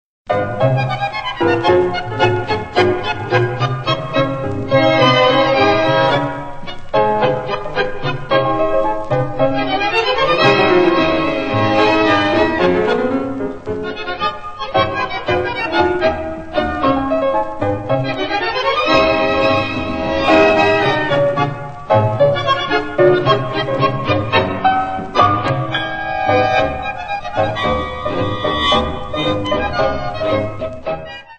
Milonga